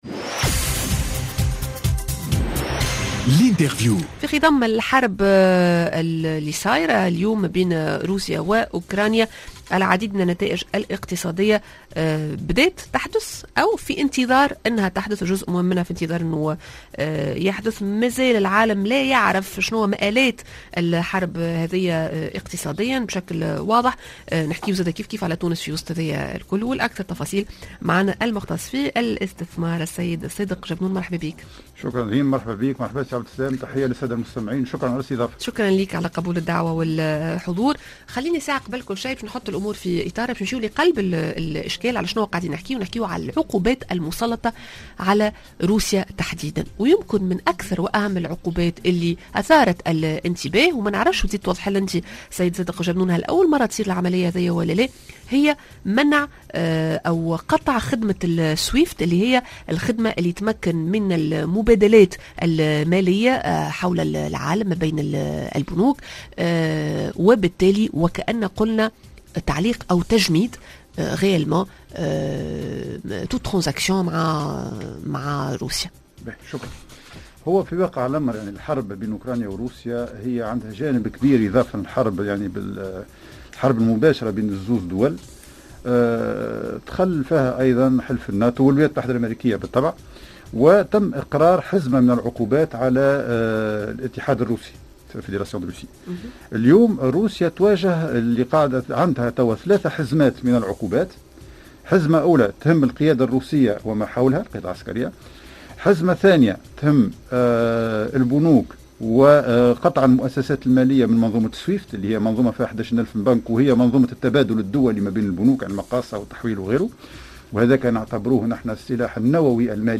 L'interview: شنوّة مآل المبادلات التجارية التونسية الروسية بعد اقصاء البنوك الروسية من نظام السويفت؟